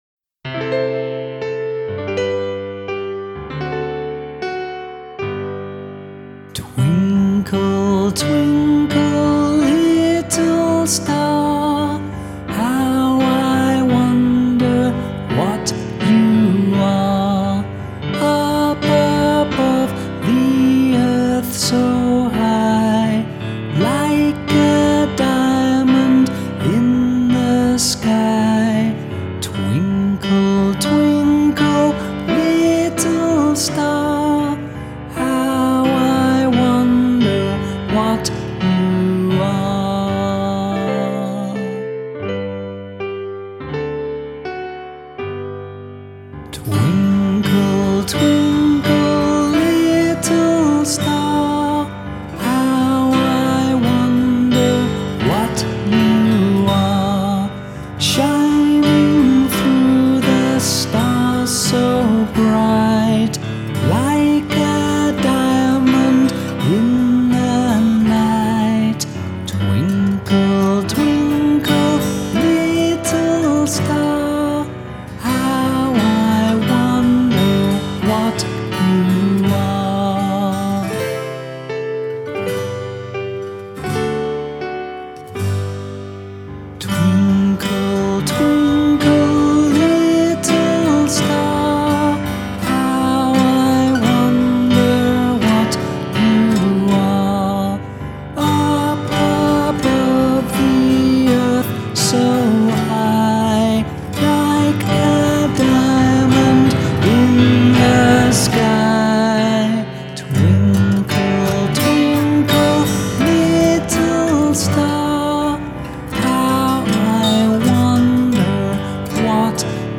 Traditional